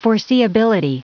Prononciation du mot foreseeability en anglais (fichier audio)
Prononciation du mot : foreseeability